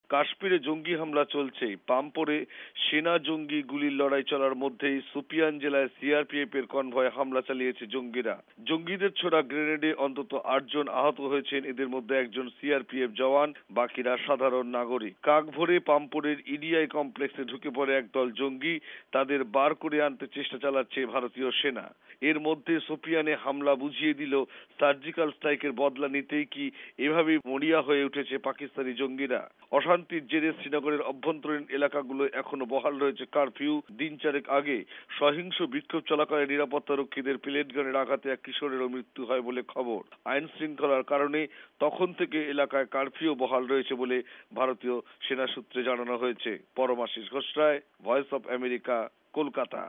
রিপোর্ট (কাশ্মীর)